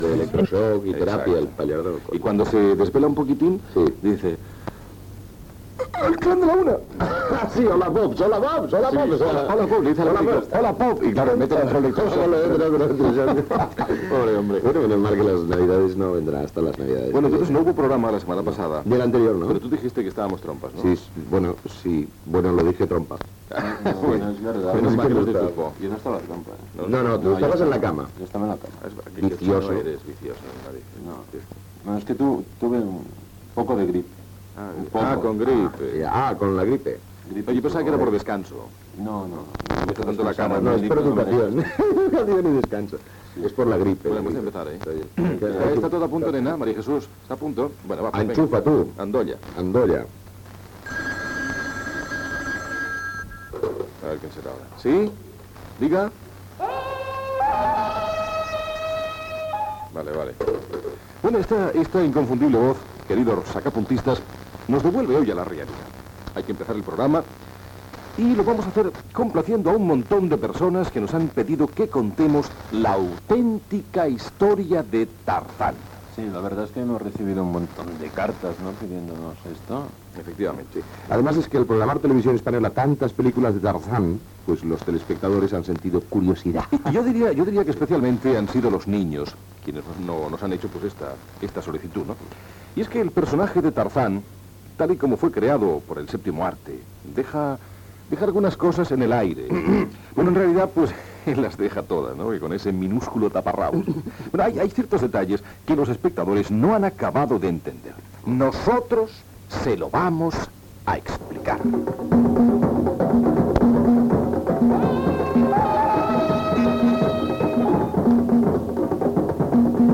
Diàleg entre els presentadors, l'autèntica història de Tarzán, la cantant Masiel visita Barcelona
Entreteniment